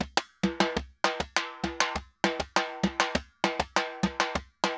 Timba_Candombe 100_4.wav